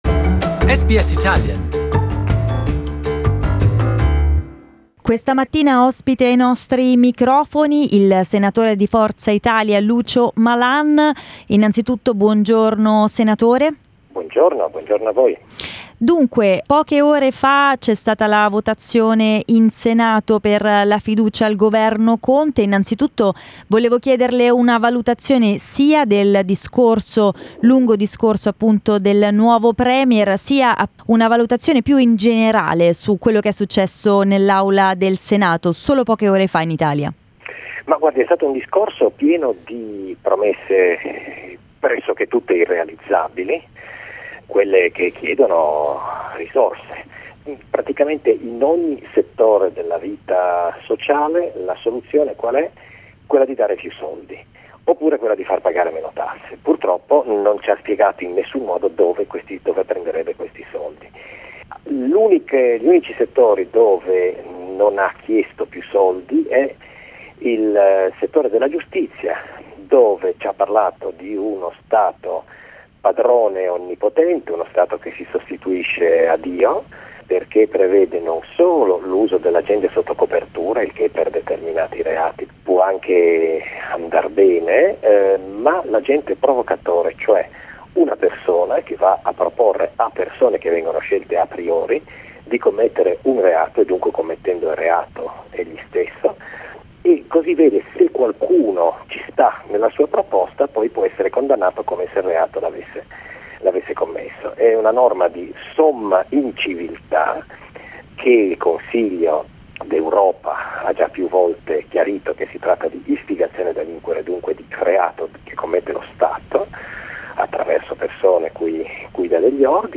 A poche ore dalla chiusura della Camera Alta, abbiamo raggiunto il Senatore di Forza Italia, Lucio Malan, per chiedergli quali siano state le posizioni del suo partito durante il dibattito in aula e un'analisi del discorso del nuovo Premier, Giuseppe Conte.